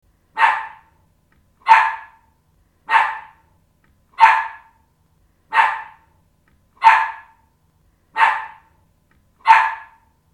Efecto De Sonido Perrito Ladrando Sound Effect Download: Instant Soundboard Button
Dog Sounds73 views